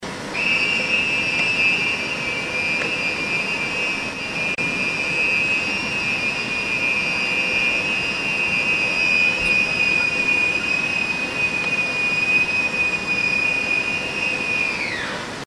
Wind Turbine Noise
wind_turbine_2.mp3